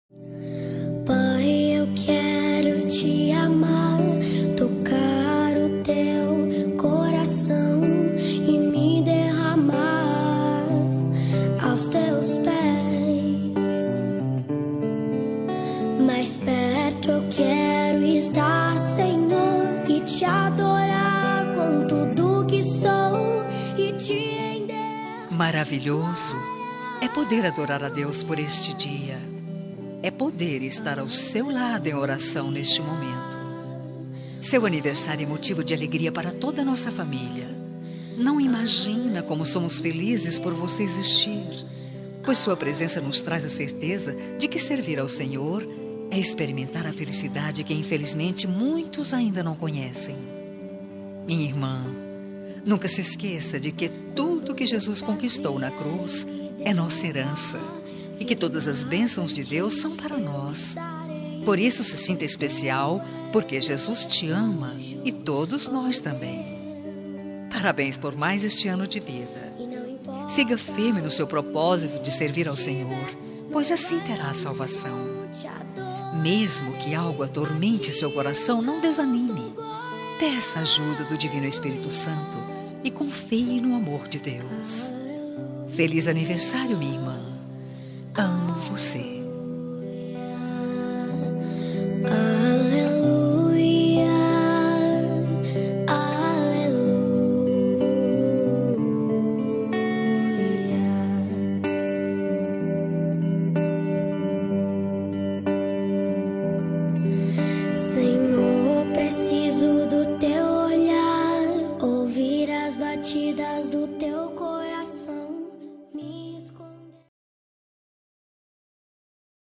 Aniversário de Irmã Evangélica – Linda- Voz Feminina – Cód: 7534